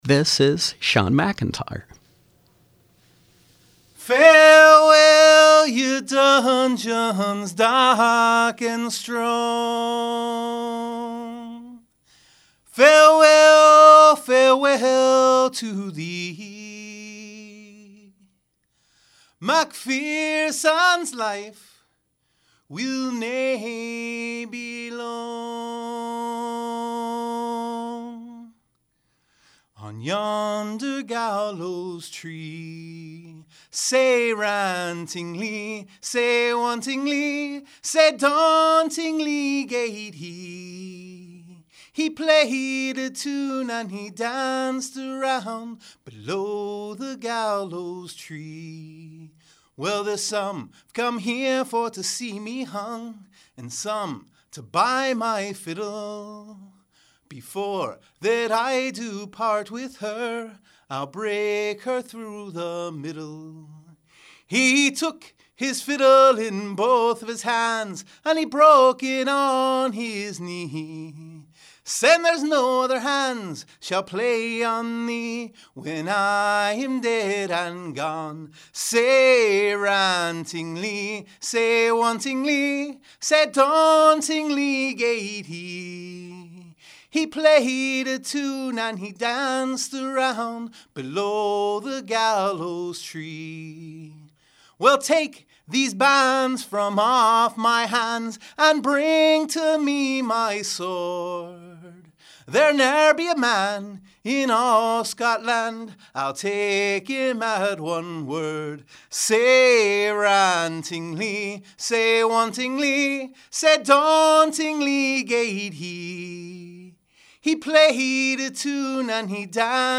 Live Music
tenor
traditional Irish, Scottish, medieval and seafaring songs.